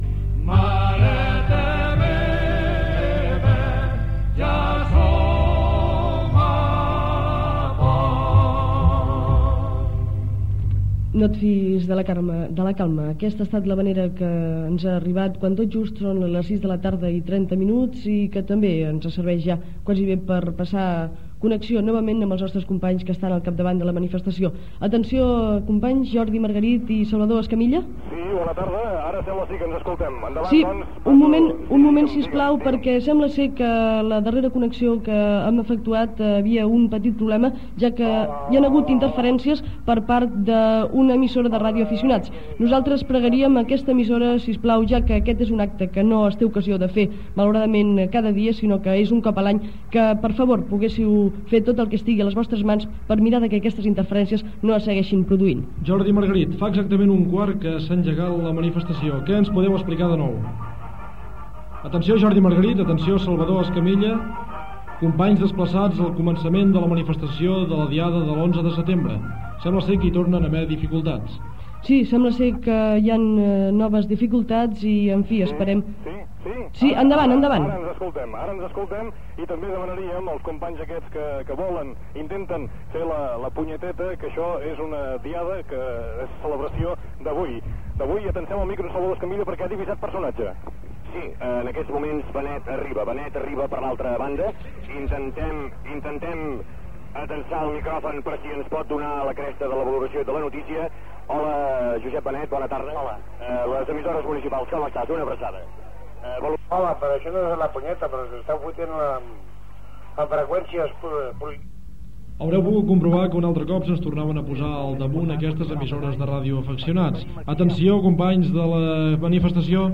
Transmissió conjunta de les EMUC de la manifestació de la Diada de l'11 de setembre de 1981, a Barcelona
Informatiu
Problemes de connexió en fer servir la banda de radioaficionats de 2 metres. Entrevista a Josep Benet.